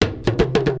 PERC 18.AI.wav